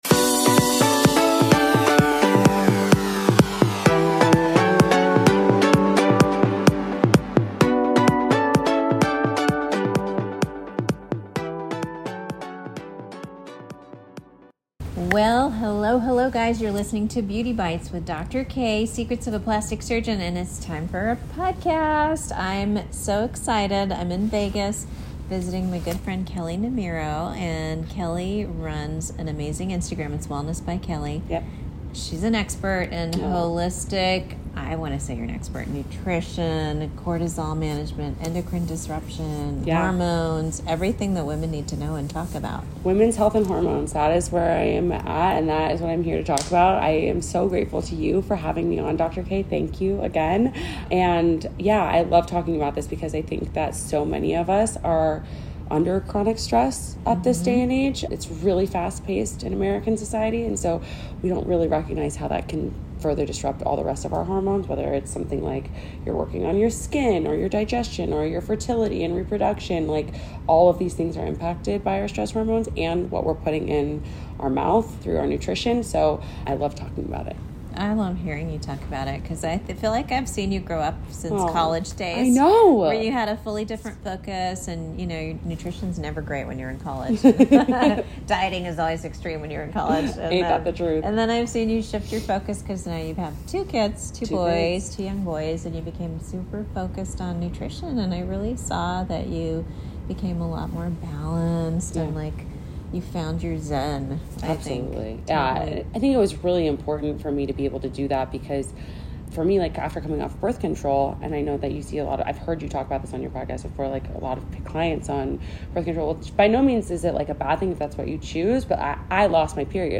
Join us live from the Hard Rock Hotel & Casino in Las Vegas